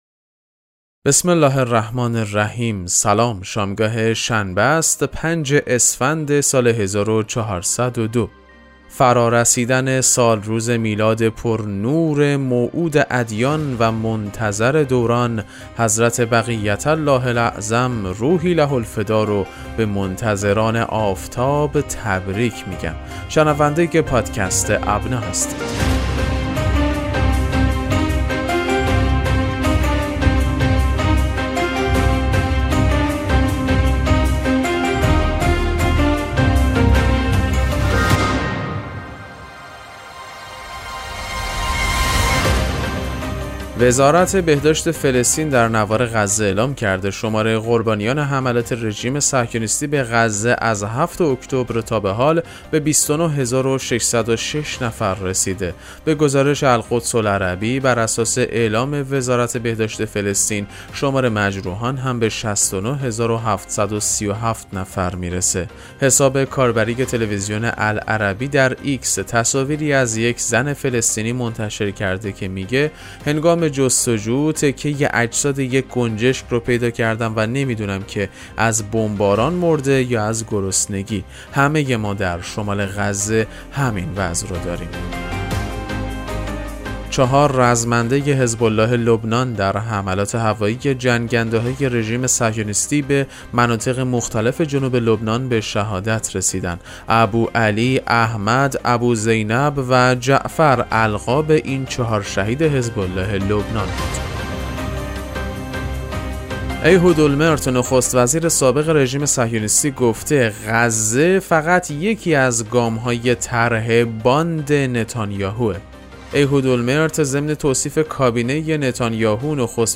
پادکست مهم‌ترین اخبار ابنا فارسی ــ 5 اسفند 1402